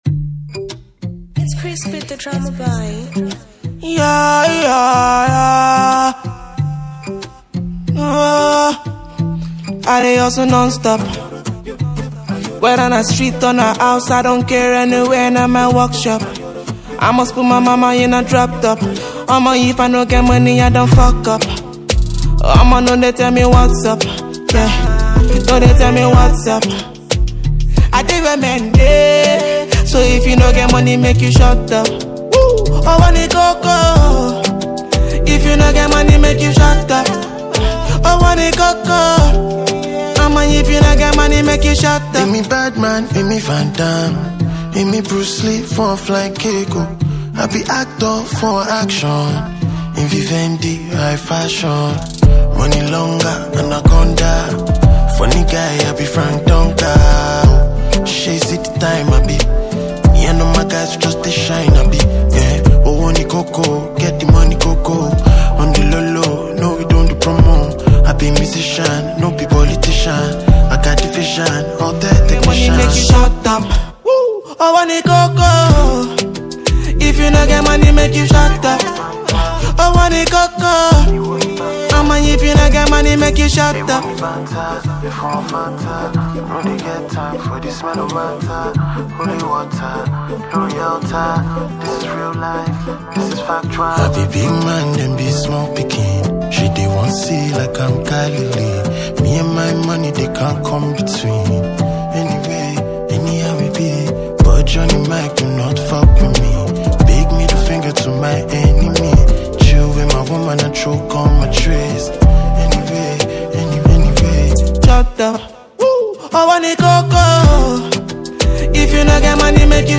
Najia Afrobeat